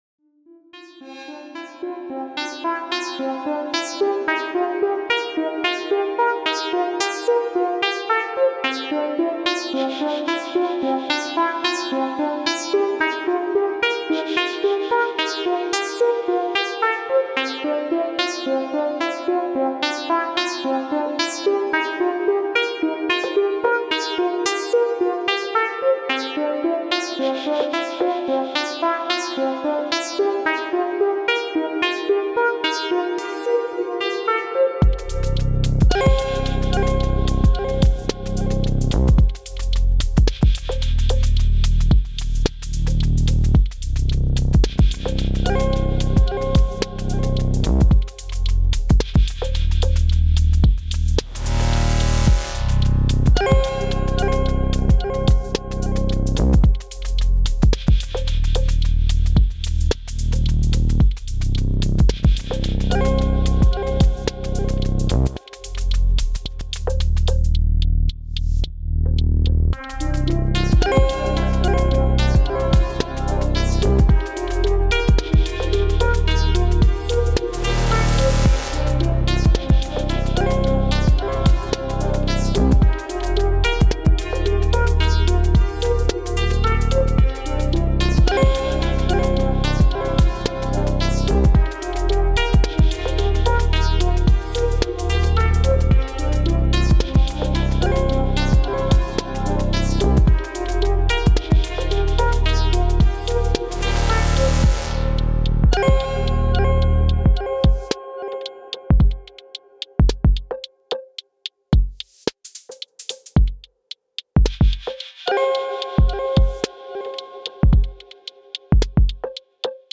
Elektron Gear Syntakt
Here a couple of jams, maybe some eq and comp in a ableton but that’s it.
All the tracks are very musical, with some nice rhythmic details and groove.